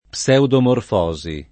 vai all'elenco alfabetico delle voci ingrandisci il carattere 100% rimpicciolisci il carattere stampa invia tramite posta elettronica codividi su Facebook pseudomorfosi [ p SH udomorf 0@ i ; alla greca p SH udom 0 rfo @ i ] s. f. (min.)